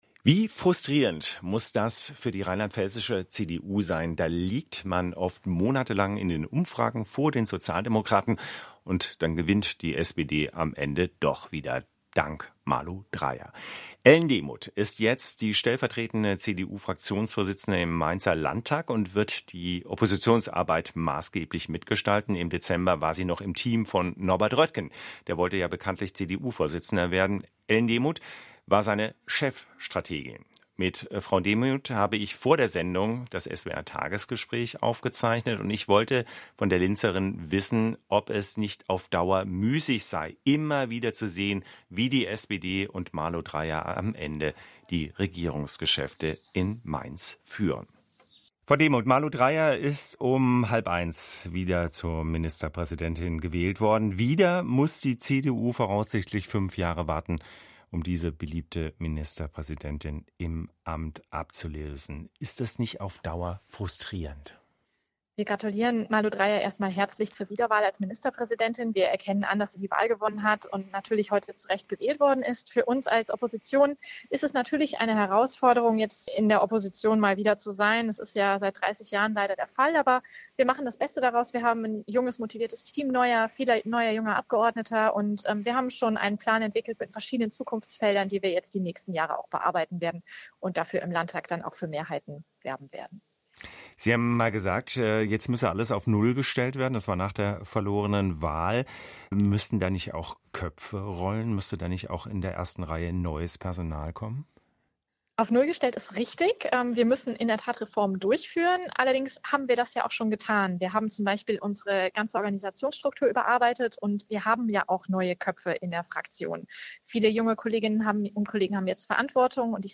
Anlässlich der konstituirenden Sitzung des rheinland-pfälzischen Landtages hatte SWR2 im Rahmen der Reihe "Tagesgespräch" Ellen Demuth um ein Telefoninterview gebeten.